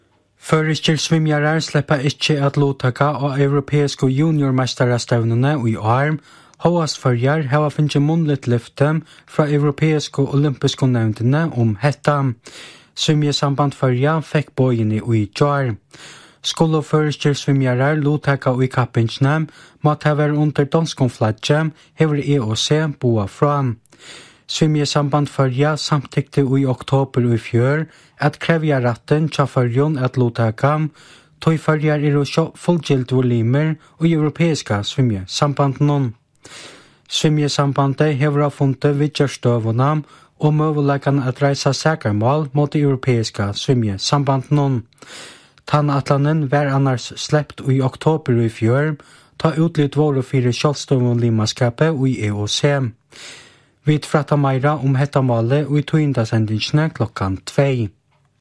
Brot úr útvarpstíðindunum hjá Kringvarpi Føroya, sunnudagin hin 1. mars 2015 kl 10:00, um støðuna nú Føroyar ikki sleppa at luttaka á Evropeisku JuniorMeistarastevnuni í svimjing 2015.